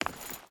Stone Chain Run 4.ogg